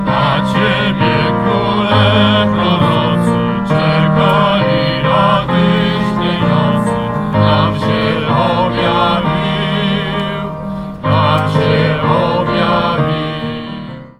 W niedzielę (22.12), na Placu Marii Konopnickiej w Suwałkach, odbyła się tradycyjna Miejska Wigilia.
Na scenie władze miasta oraz zespoły Beciaki, Świetlik i Suwałki Gospel Choir zaśpiewały najpiękniejsze polskie kolędy.
kolęda.mp3